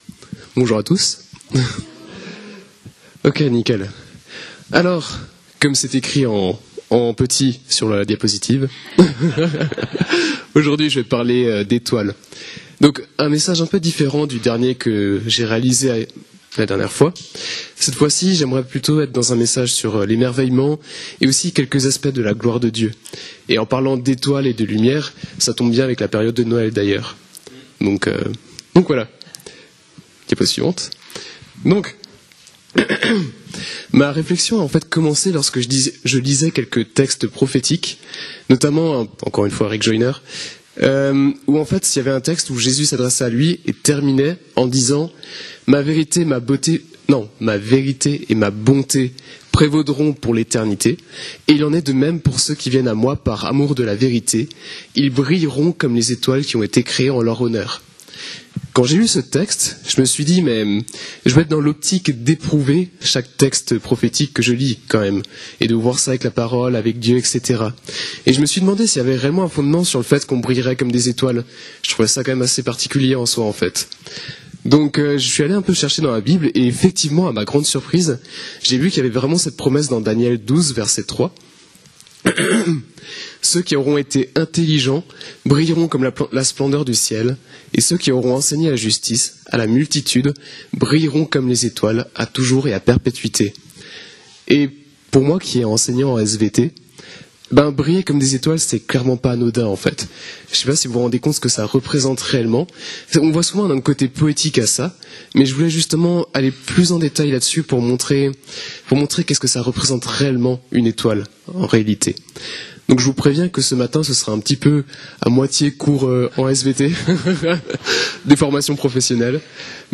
Type de service: Culte du dimanche